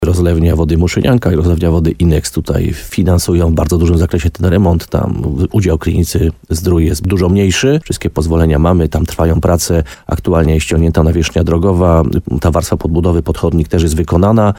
Burmistrz Krynicy-Zdroju Piotr Ryba cieszy się, że realizację prac w dużej mierze wzięły na siebie zakłady leżące w strefie przemysłowej.